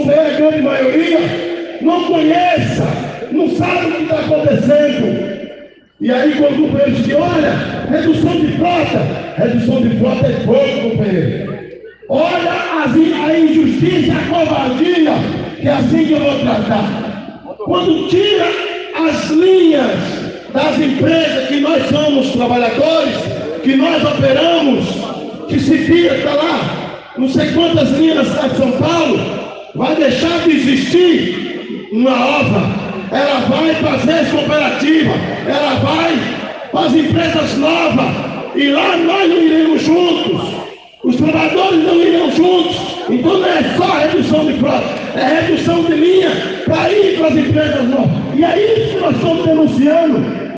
No discurso da assembleia, o presidente licenciado do Sidmotoristas, José Valdevan de Jesus Santos (Noventa), disse que nesta terça-feira, 30 de julho de 2019, uma comissão deve se reunir com representantes da SMT – Secretaria Municipal de Mobilidade e Transportes para discutir as mudanças nas linhas de ônibus.